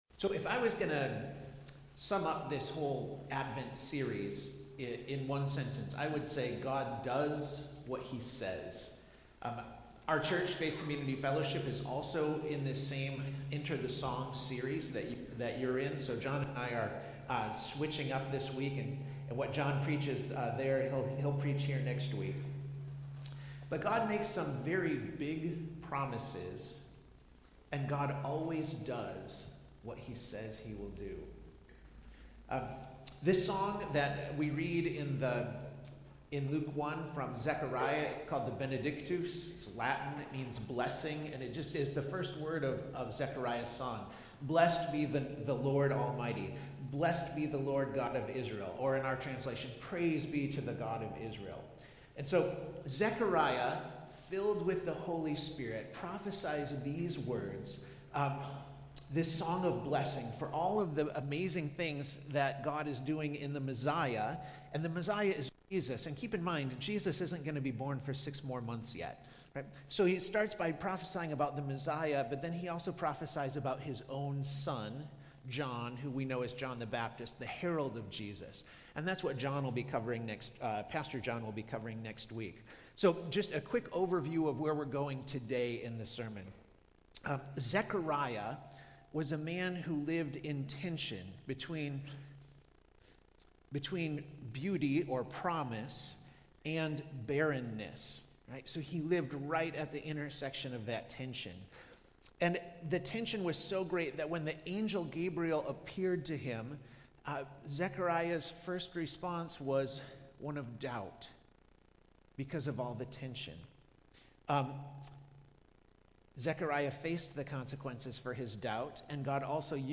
Passage: Luke 1:67-75 Service Type: Sunday Service